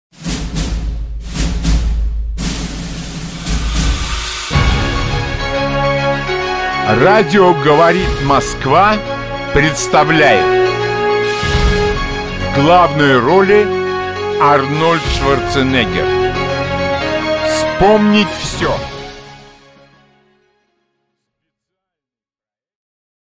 Аудиокнига Качай, Арнольд, качай | Библиотека аудиокниг